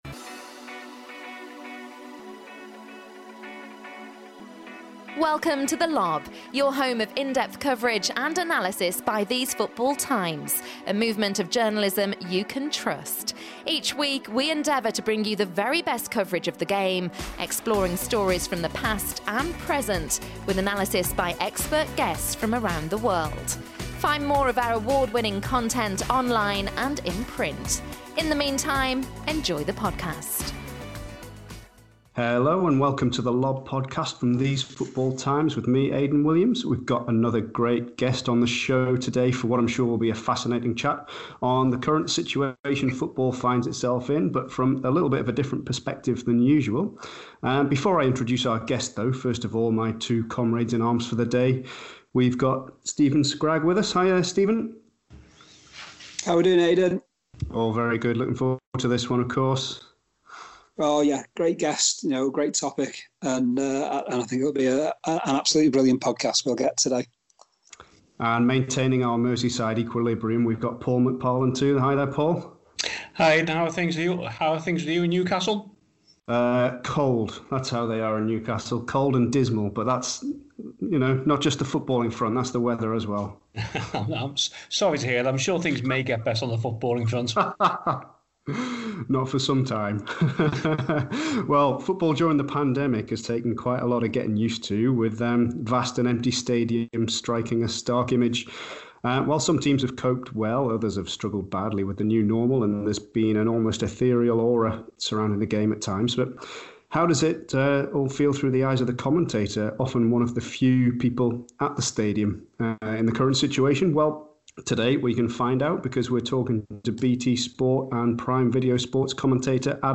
Commentating in these unusual times - a discussion